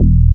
BAS_Dance  172.wav